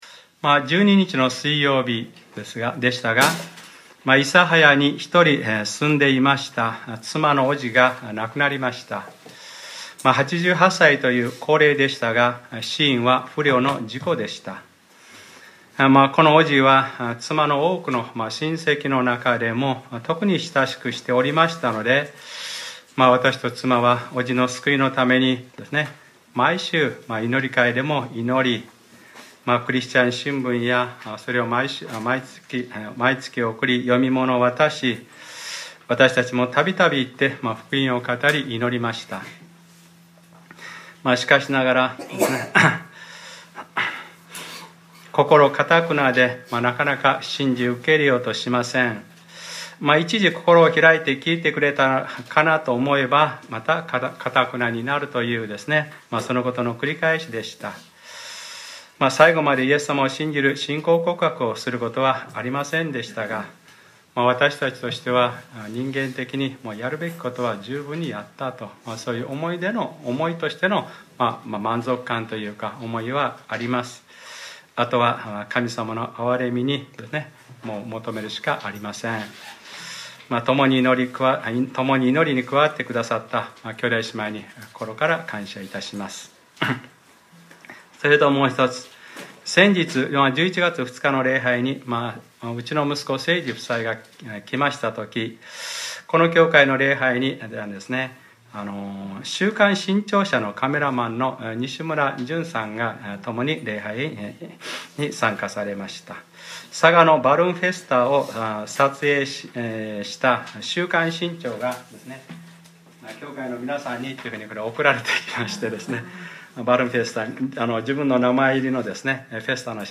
2014年11月16日）礼拝説教 『ルカｰ４９：悔い改めにふさわしい実を結びなさい』